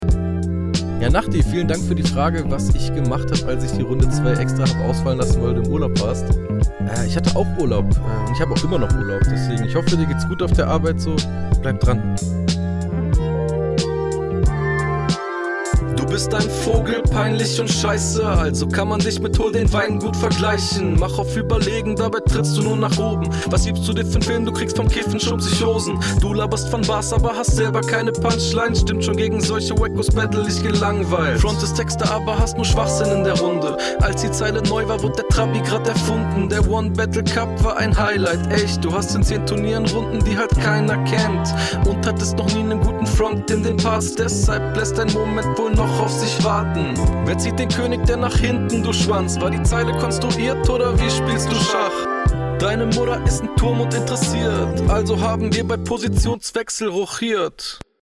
Der Stimmeinsatz klingt weniger geredet als in der HR3, die Delivery hier auf ähnlichem Niveau.
Flow: Auch sehr entspannt